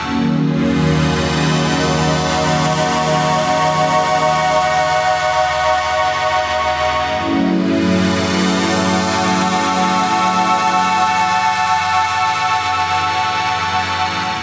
Pad_133_C#.wav